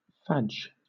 Ääntäminen
Synonyymit tatty cake potato cake stottie potato bread tatty scone Ääntäminen Southern England: IPA : /fæd͡ʒ/ Haettu sana löytyi näillä lähdekielillä: englanti Käännöksiä ei löytynyt valitulle kohdekielelle.